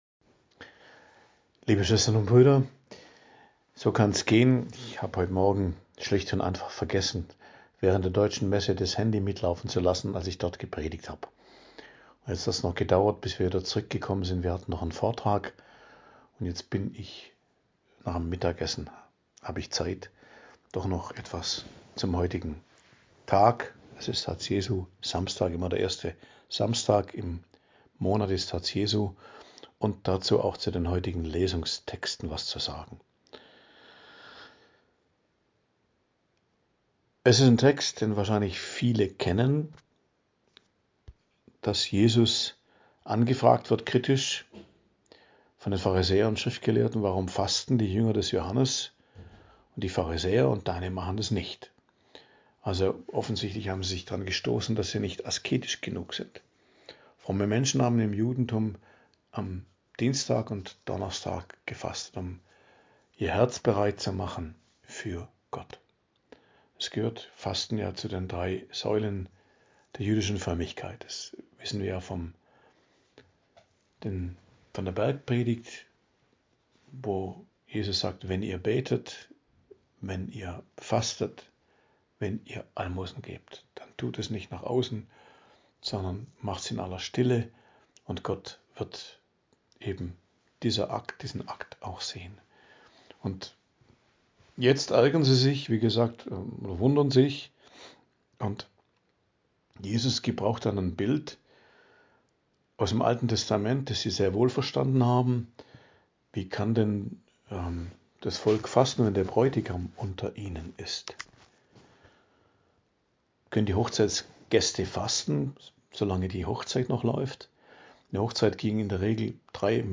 Predigt am Freitag der 22. Woche i.J., 5.09.2025 ~ Geistliches Zentrum Kloster Heiligkreuztal Podcast